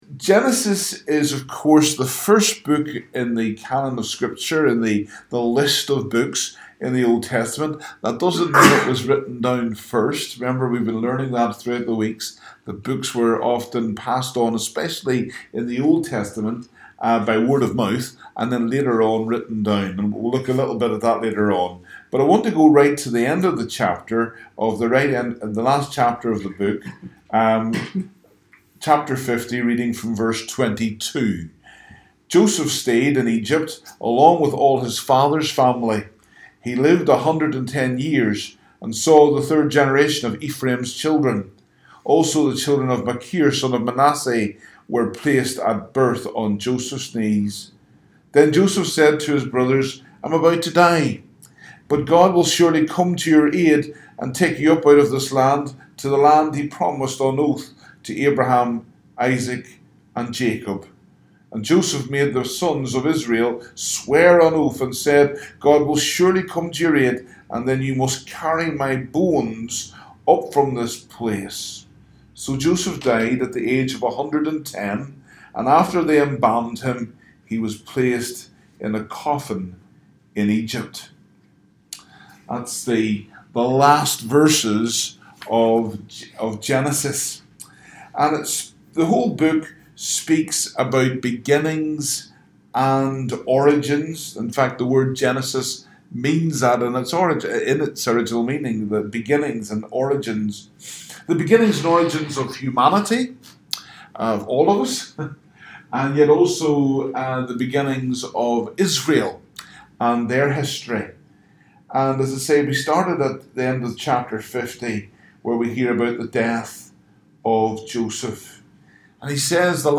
Download the live Session as an MP3 audio file